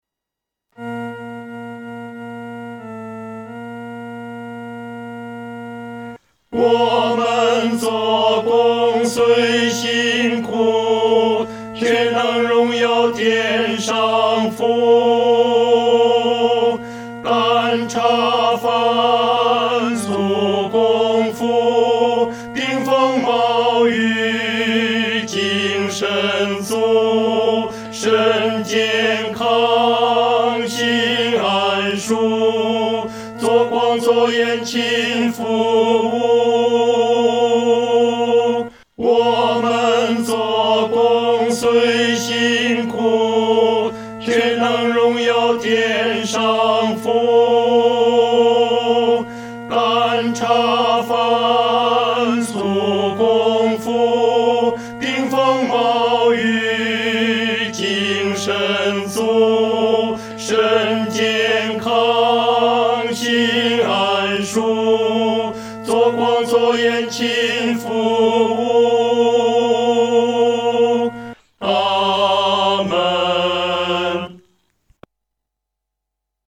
男高
曲调很朴素，用的是五声音阶，农村信徒很容易上口。